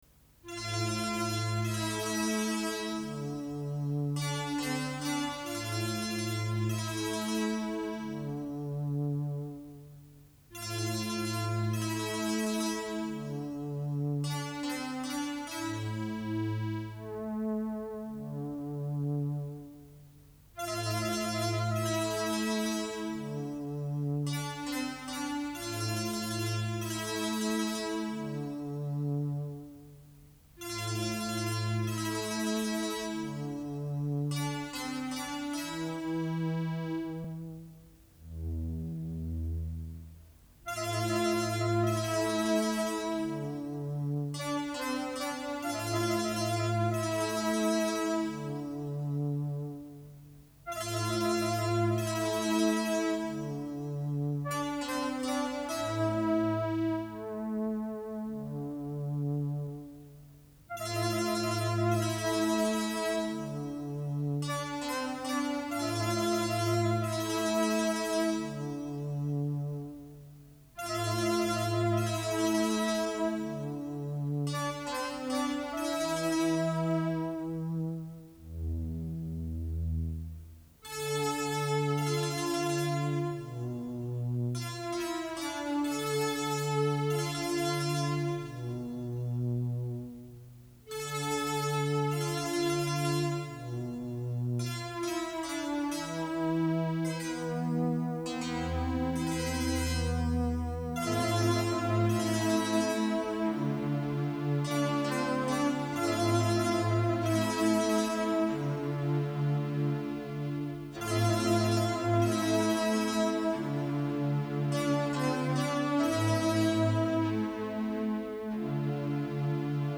מוסיקה מהסרטים